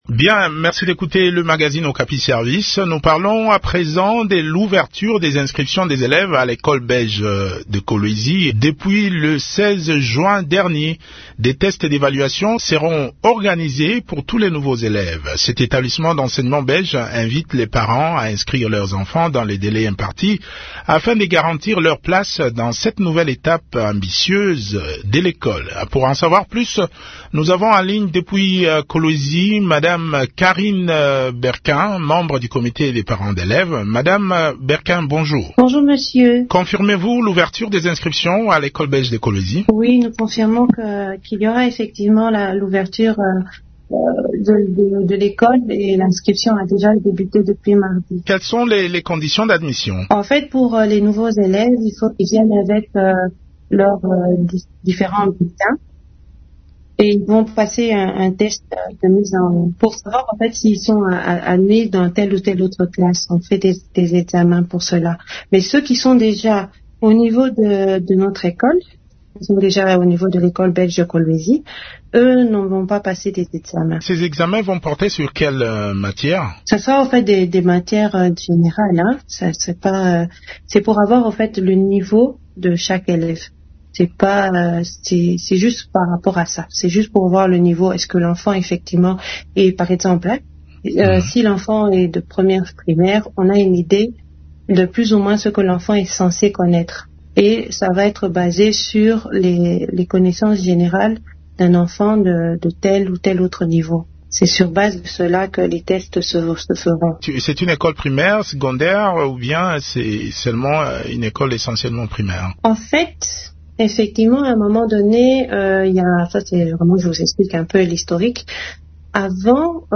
interview_ecole_belge_kolwezi-web.mp3